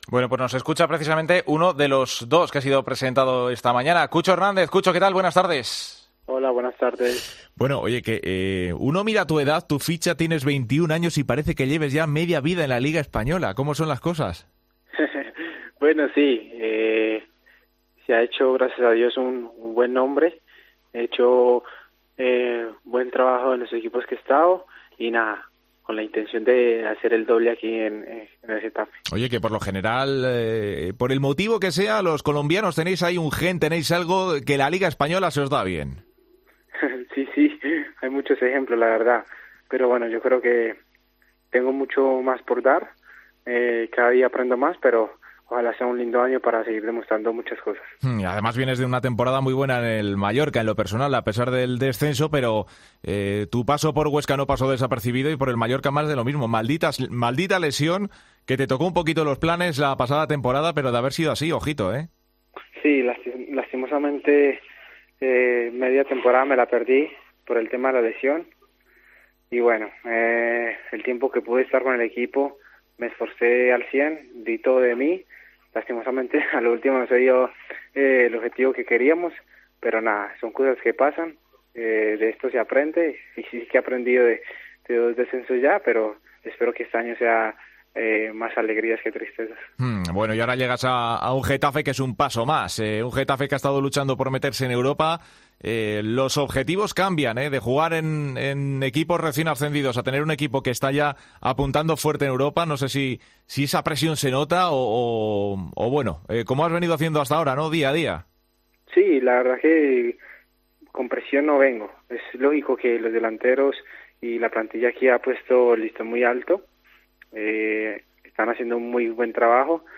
El colombiano ha estado en Deportes COPE analizando su llegada al Getafe. "Esta plantilla ha dejado el listón muy alto, yo vengo a aportar", ha asegurado.